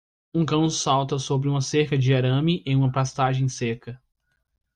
Pronounced as (IPA) /ˈseʁ.kɐ/